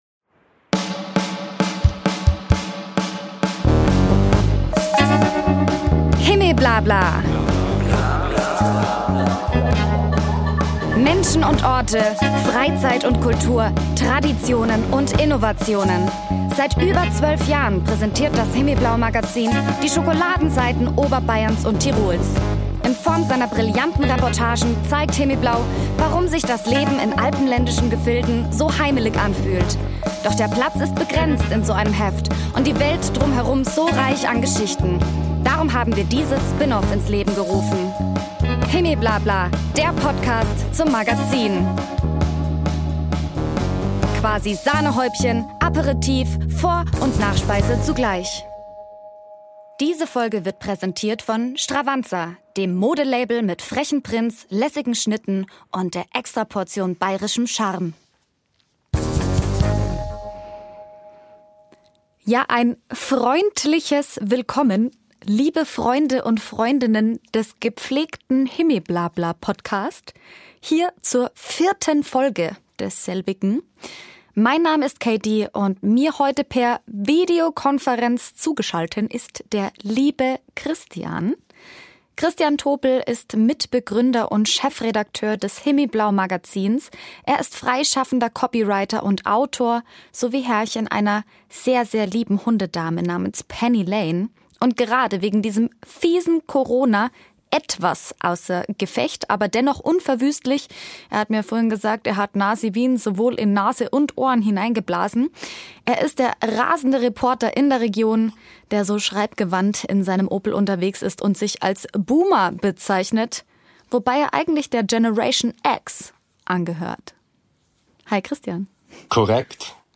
sich zumindest digital (und, sorry, ein wenig schniefend)